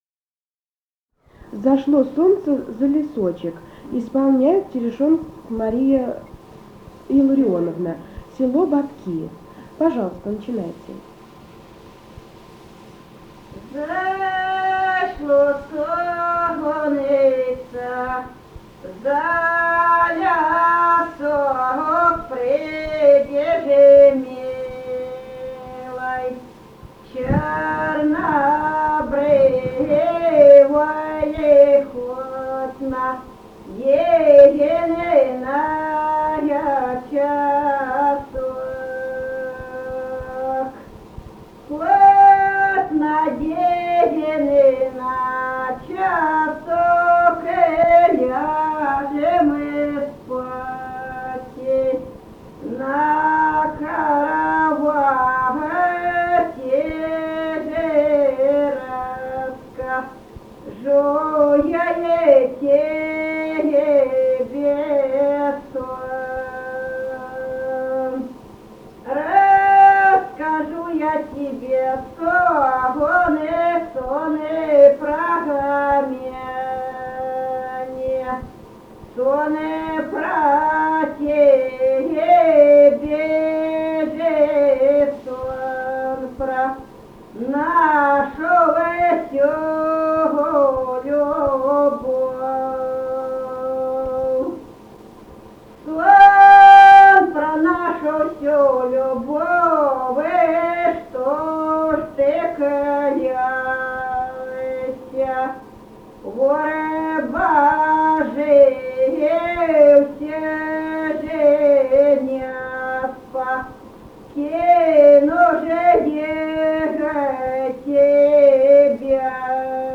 Музыкальный фольклор Климовского района 001. «Зашло солнце за лесок» (беседная).
Записали участники экспедиции